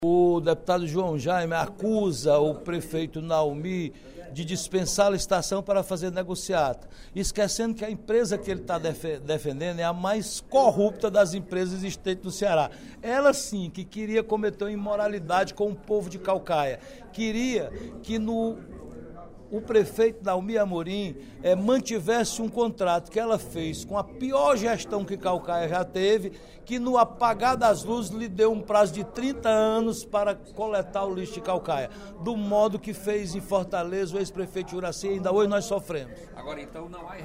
O deputado Roberto Mesquita (PSD) apoiou, em pronunciamento durante o primeiro expediente da sessão plenária desta terça-feira (21/02), a decisão do prefeito de Caucaia, Naumi Amorim, de rescindir o contrato com a empresa responsável pela coleta do lixo da cidade - a EcoCaucaia, do grupo Marquise.